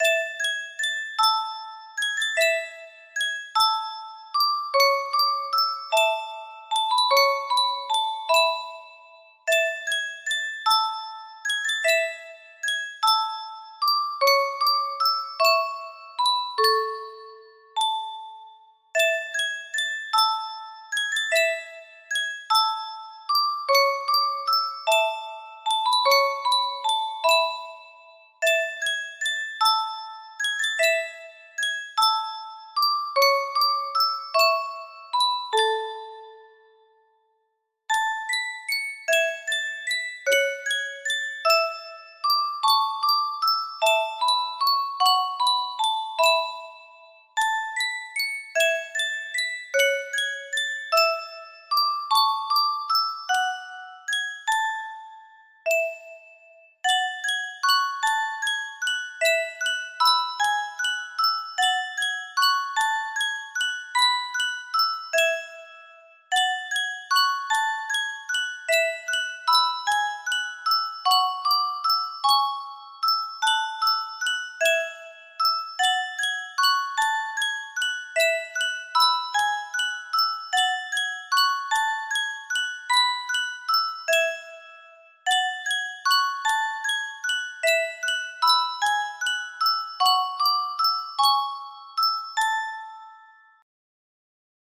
Unknown Lullaby music box melody
Full range 60